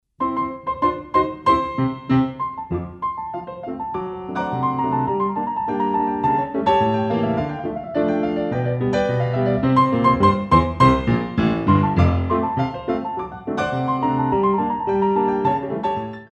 Relevés pointes milieu